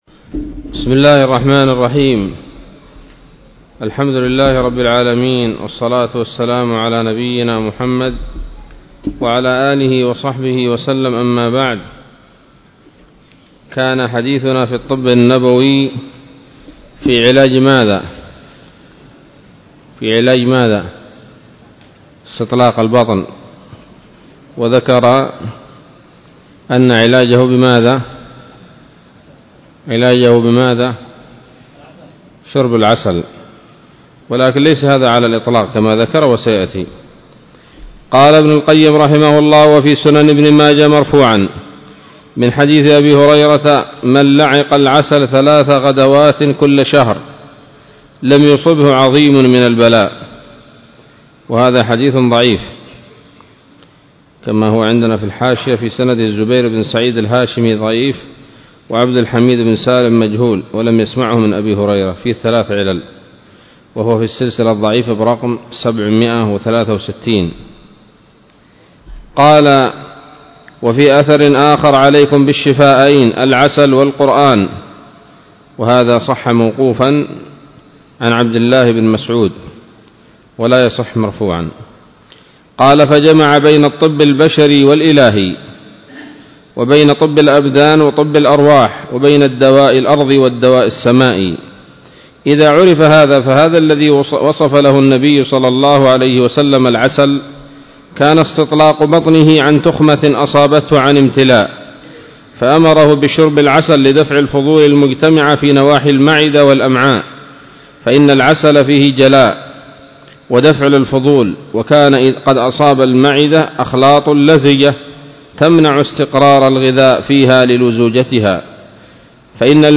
الدرس التاسع من كتاب الطب النبوي لابن القيم